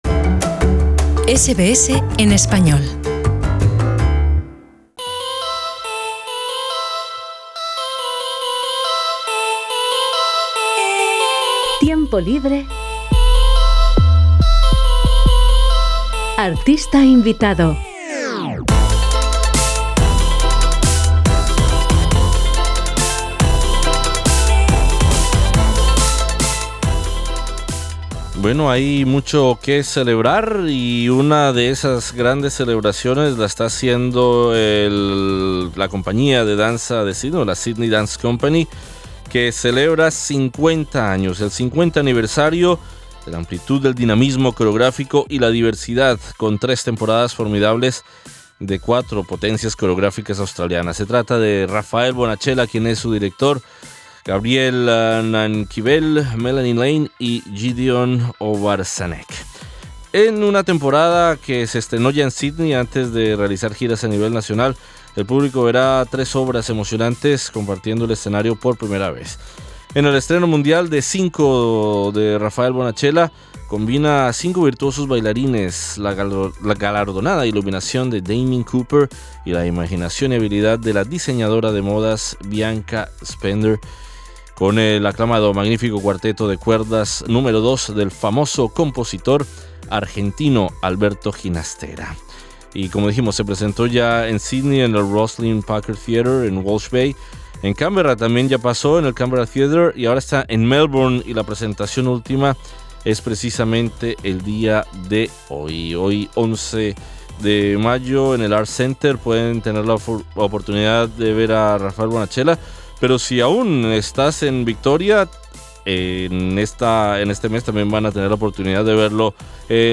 El coreógrafo y director artístico catalán está de gira con su trabajo “Cinco”, parte de un programa triple para conmemorar los 50 años de la Sydney Dance Company. Escucha la entrevista con Bonachela.